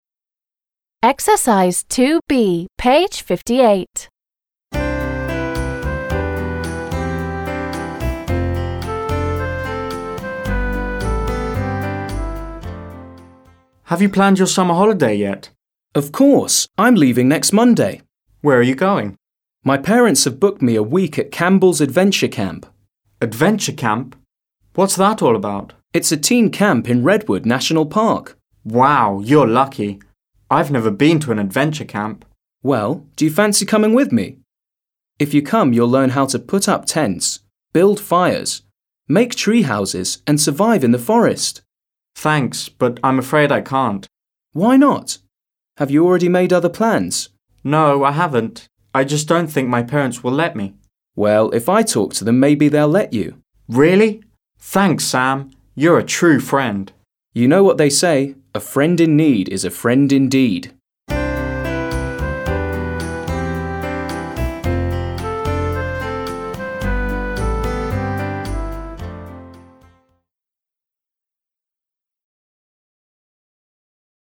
This dialogue is between two friends. They are discussing their summer plans.